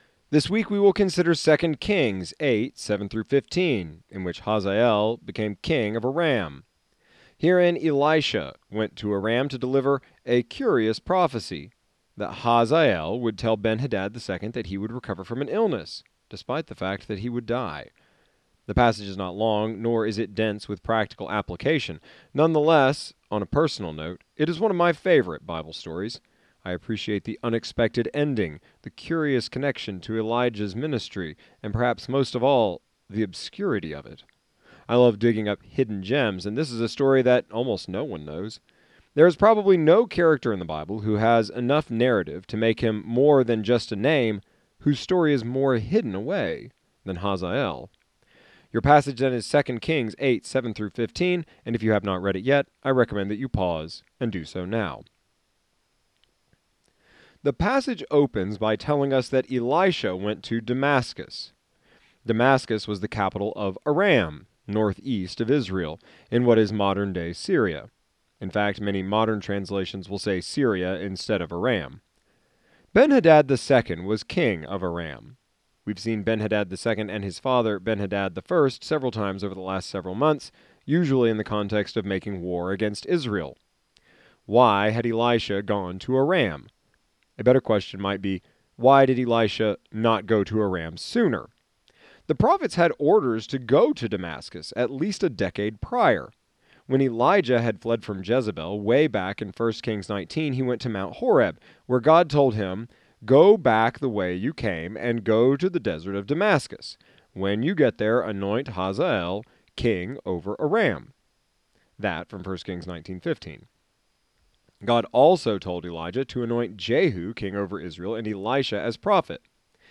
exegetical sermon series through the entire Bible.